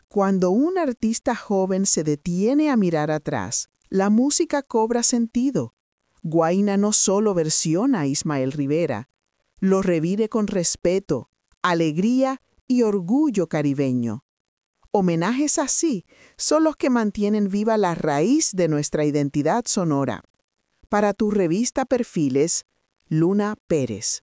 🎙 COMENTARIO EDITORIAL: